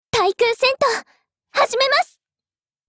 Cv-31901_warcry.mp3